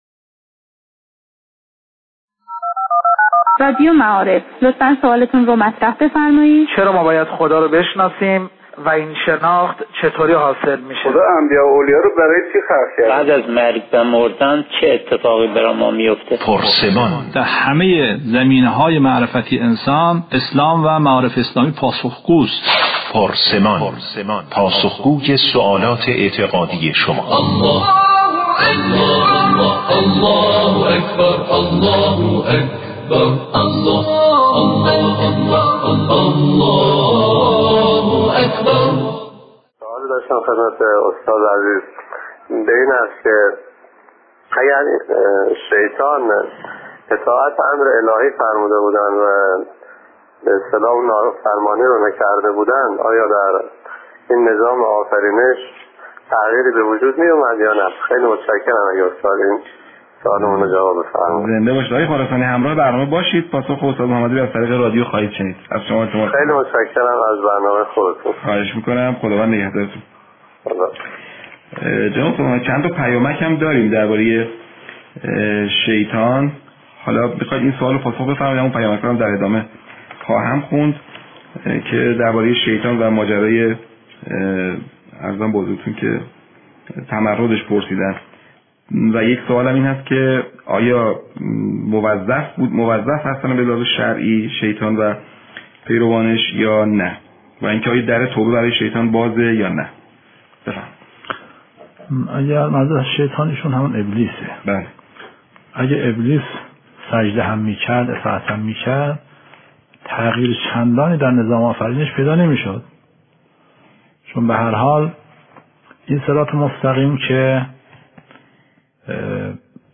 " دانلود پاسخ به سوالاتی پیرامون شیطان " تذکر: با توجه به خط و مشی رادیو معارف از موسیقی در ساخت پادکست استفاده نشده است.